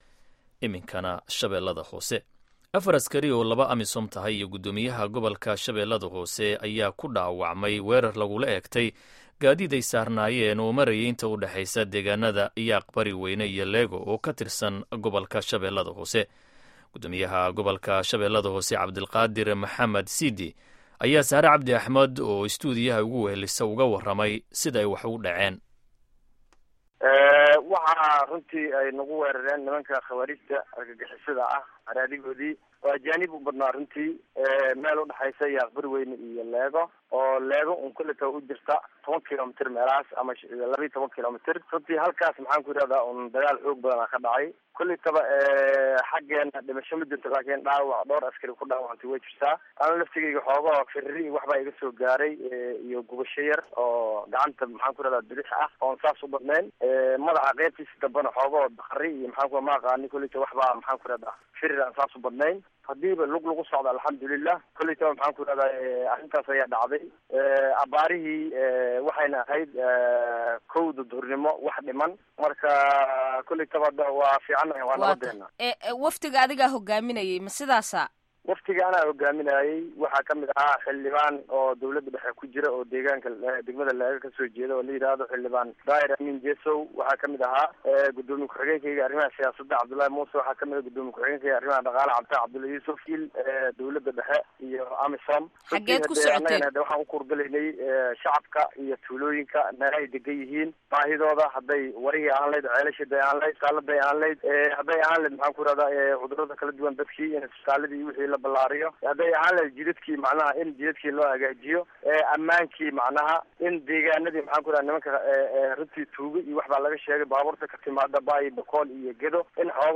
Wareysiga Guddoomiyaha